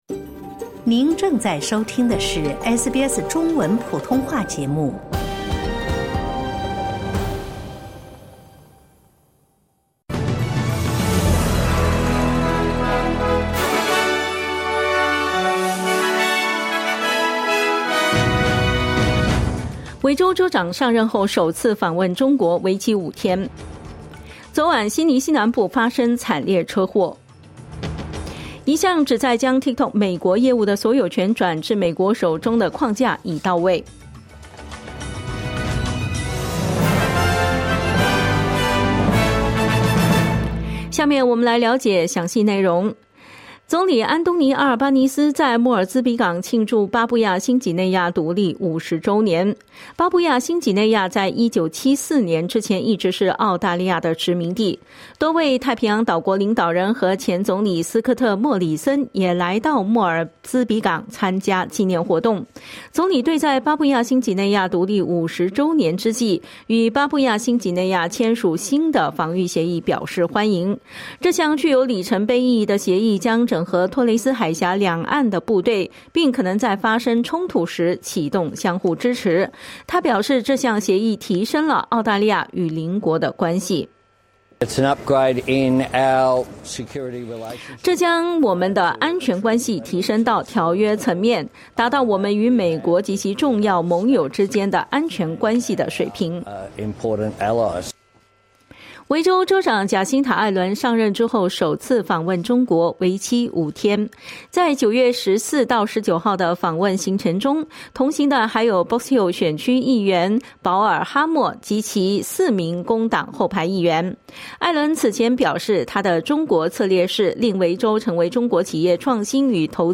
SBS早新闻（2025年9月16日）